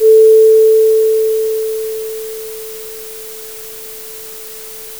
With 8-bit audio, dither can be clearly audible at normal listening levels, as can “quantization distortion” when dither is not used.